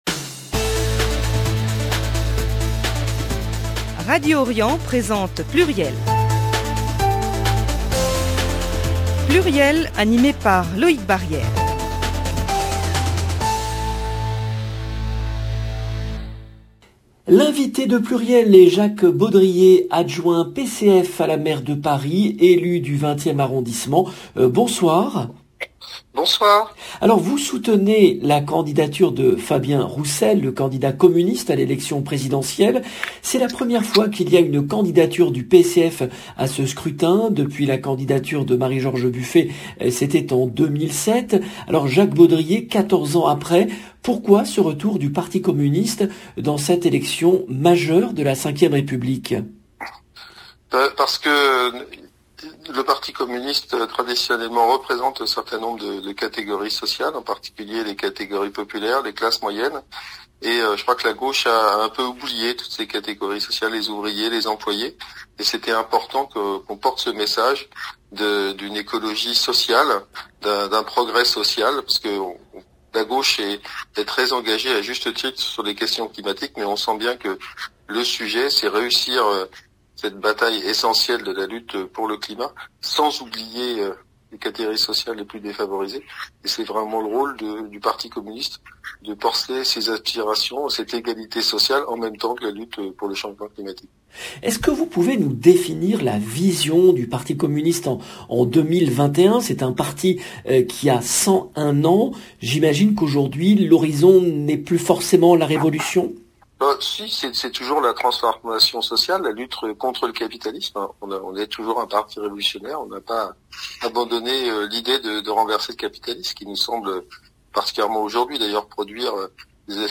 Jacques Baudrier, adjoint PCF à la maire de Paris,
le rendez-vous politique du lundi 6 décembre 2021 L’invité de PLURIEL est Jacques Baudrier, adjoint PCF à la maire de Paris, élu du 20e arrondissement. Il soutient la candidature de Fabien Roussel à l'élection présidentielle.